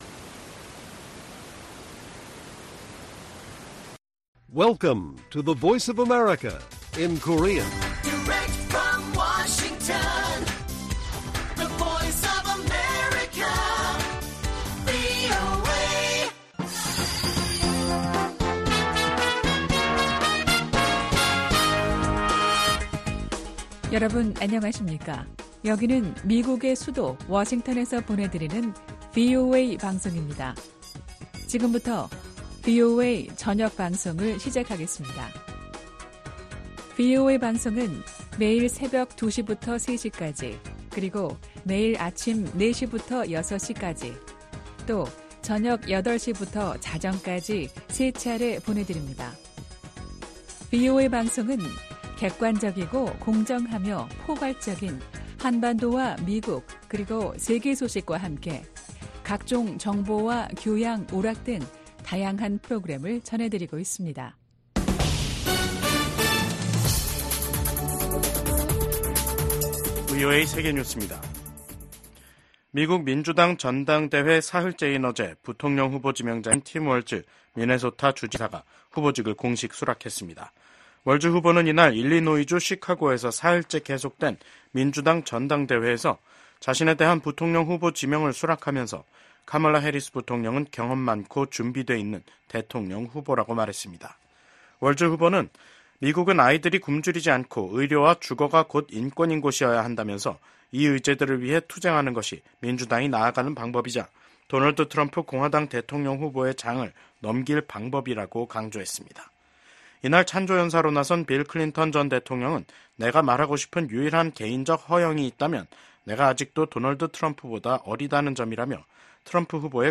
VOA 한국어 간판 뉴스 프로그램 '뉴스 투데이', 2024년 8월 22일 1부 방송입니다. 미국 백악관은 개정된 핵무기 운용 지침이 특정 국가나 위협을 겨냥한 것은 아니라고 밝혔습니다. 북한의 이재민용 천막 단지가 자강도까지 광범위하게 퍼져 있는 것으로 확인됐습니다. 올해 북한의 대중 담배 수입액이 27분의 1 수준으로 줄어든 것으로 나타났습니다.